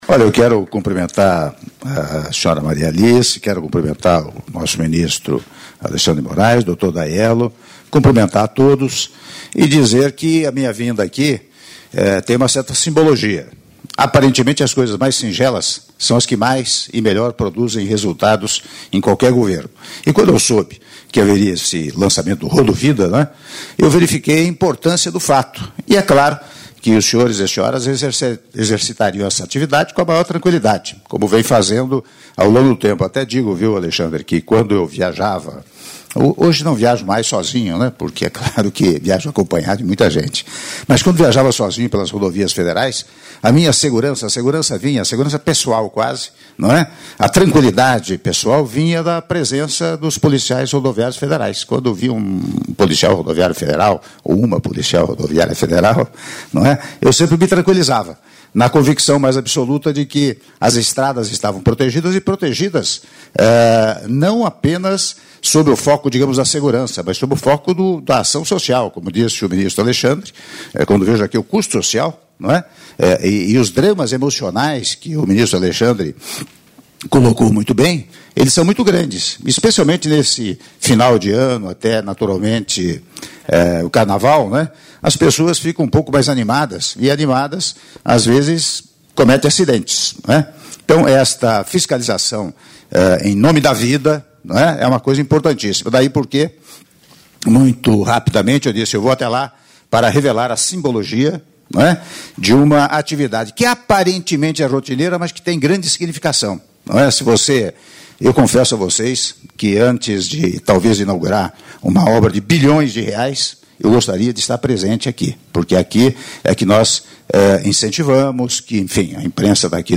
Áudio das palavras do presidente da República, Michel Temer, durante visita ao Centro de Comando e Controle da Polícia Rodoviária Federal, por ocasião do lançamento da Operação Rodovida 2016-2017- Brasília/DF (02min41s)